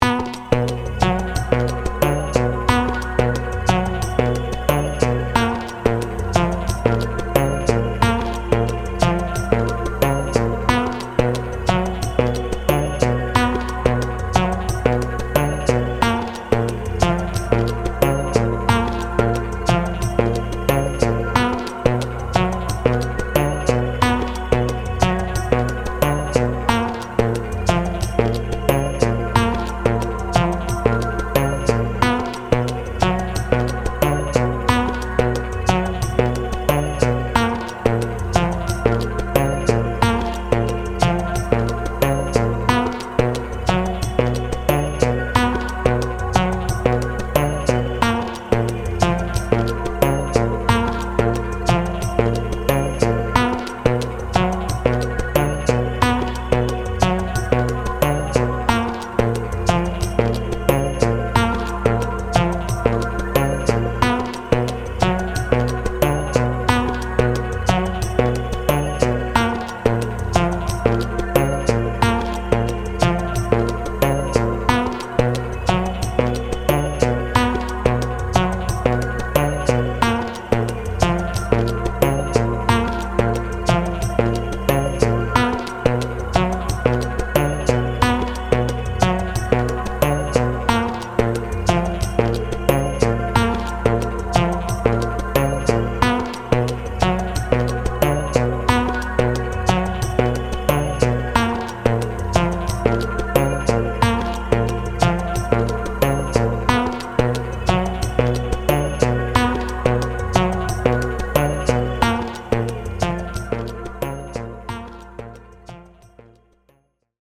冷たい,異質、奇妙、不気味な雰囲気を意識して制作しました。